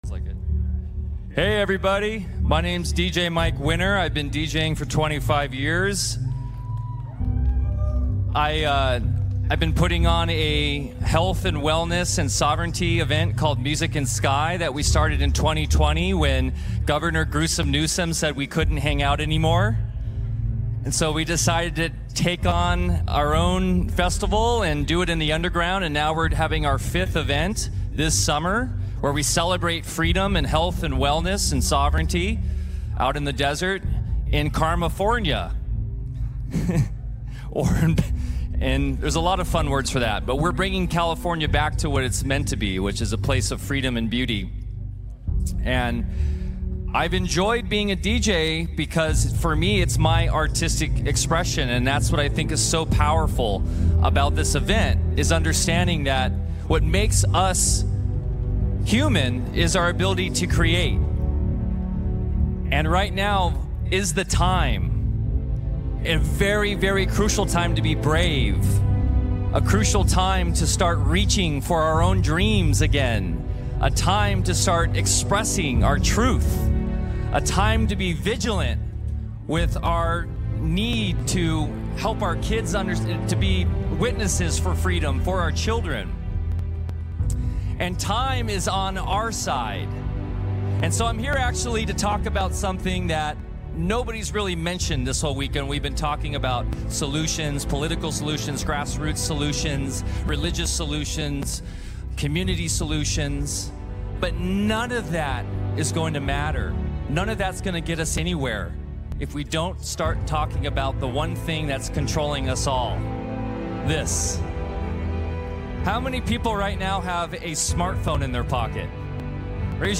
Please enjoy this recent presentation of QORTAL
at CAUSE FEST in Nashville, TN.